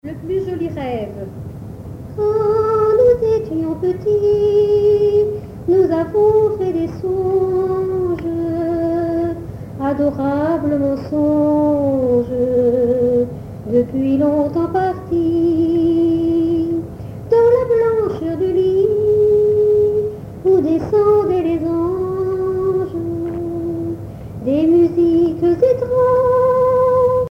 Genre strophique
chansons populaires
Pièce musicale inédite